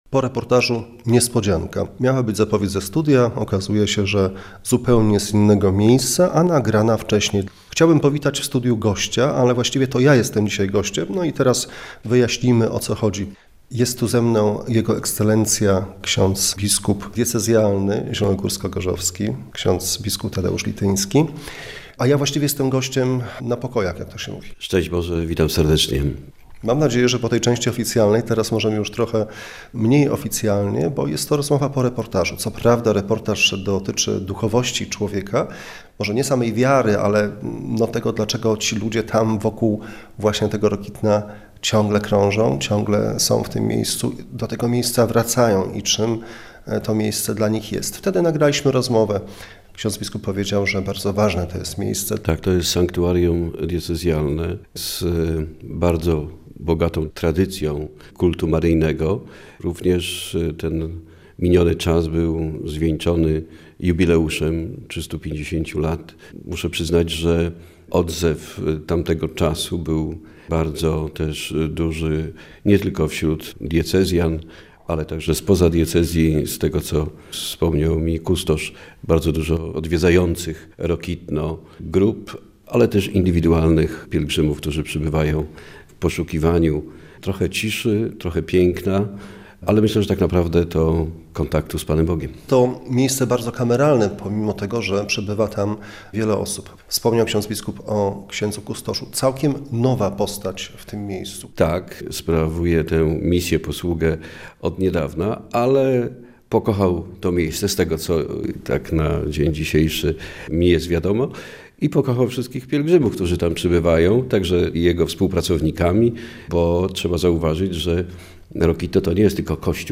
W reportażu głos zabiorą duchowni związani z tym miejscem, ale także i Ci dla których jest ono ważne zupełnie z innego powodu.
rozmowa-z-bp-tdeuszem-litynskim.mp3